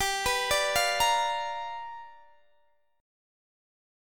G7#9 Chord
Listen to G7#9 strummed